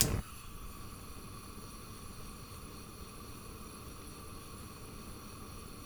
Gas Hob 02.wav